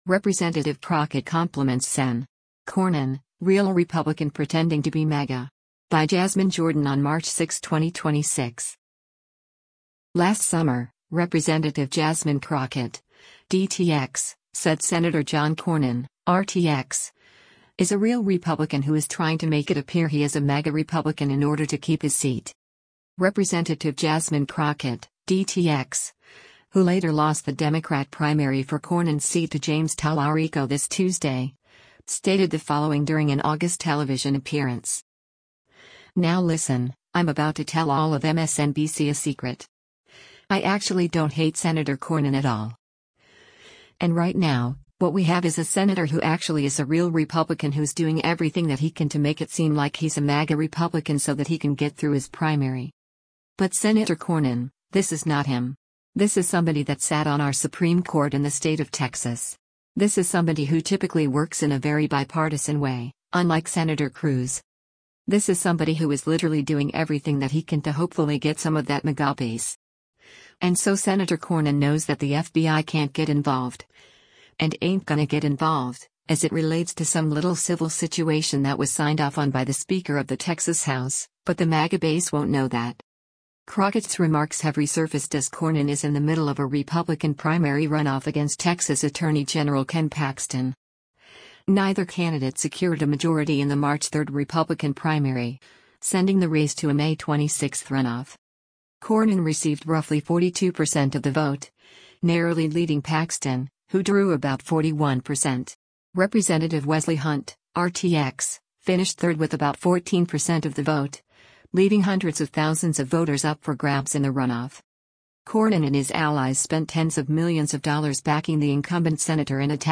Rep. Jasmine Crockett (D-TX), who later lost the Democrat primary for Cornyn’s seat to James Talarico this Tuesday, stated the following during an August television appearance: